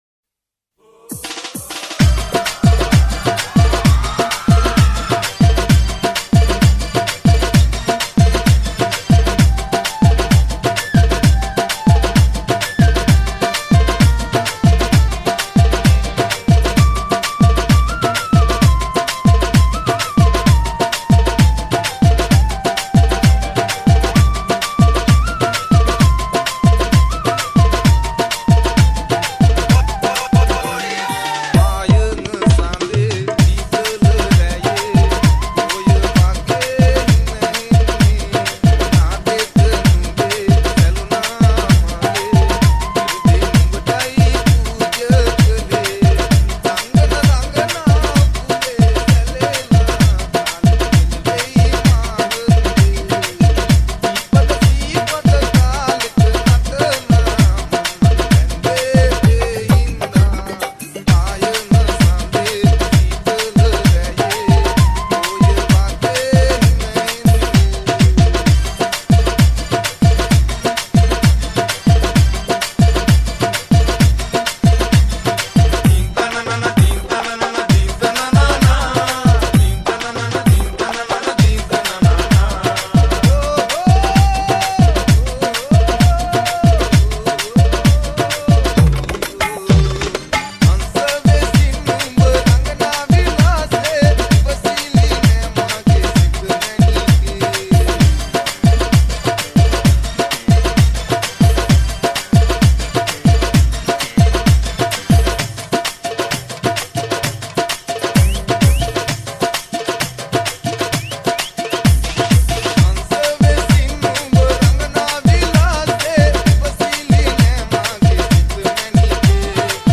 6-8 DJ Song